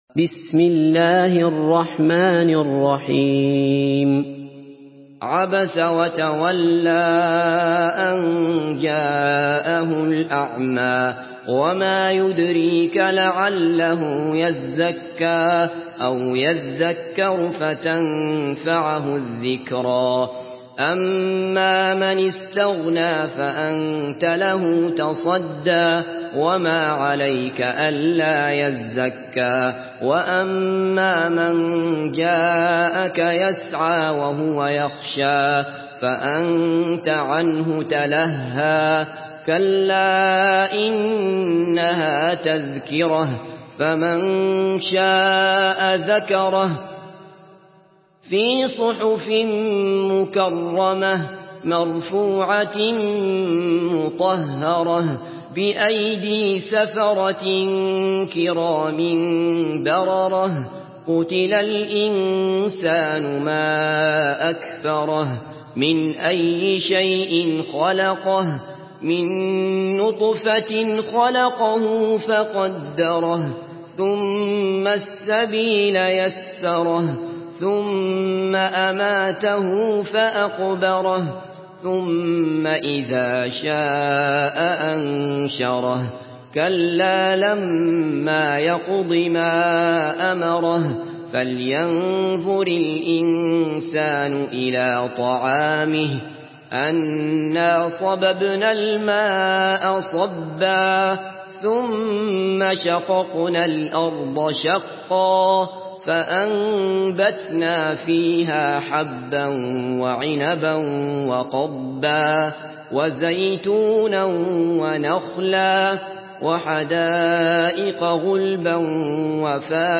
Une récitation touchante et belle des versets coraniques par la narration Hafs An Asim.